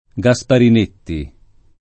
[ g a S parin % tti ]